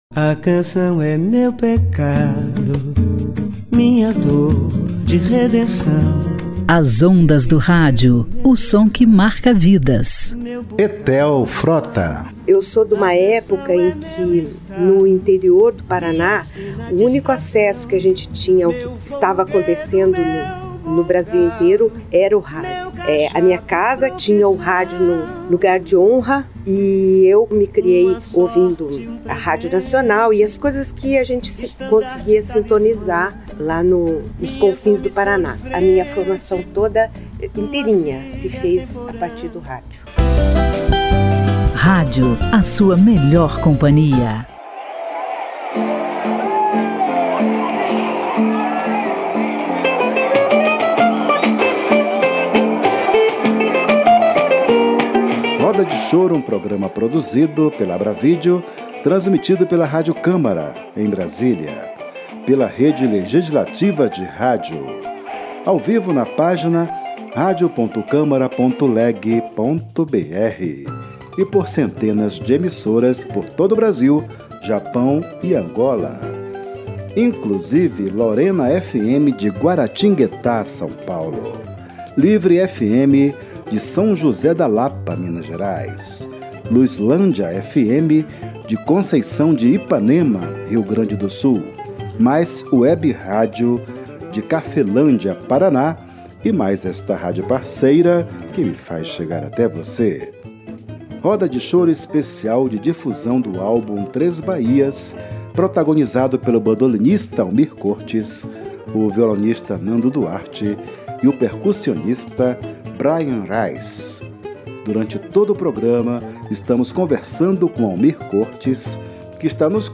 do trio homônimo formado pelo bandolinista baiano
o violonista carioca
o percussionista estadunidense